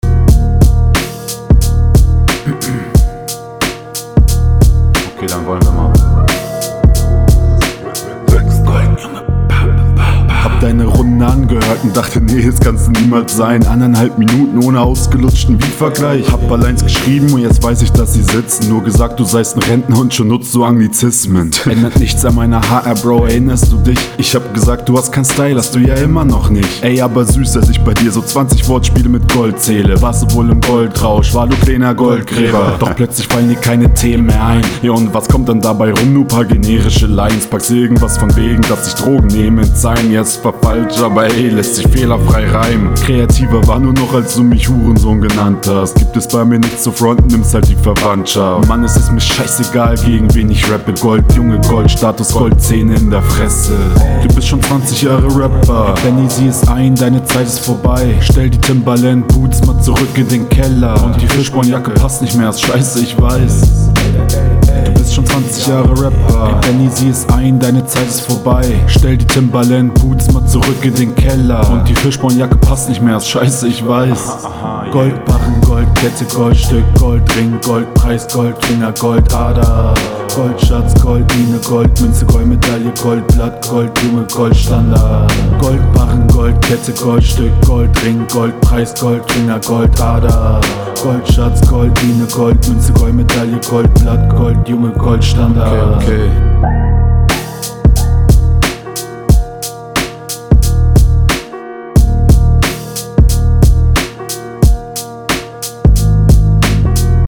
Raptechnisch wieder deutlich schwächer. Hätte mir eine energischere Stimme gewünscht.
Solide gerappt, aber kannst auch nocj mehr mit deiner Stimme machen Sehr gut gekontert, lustige …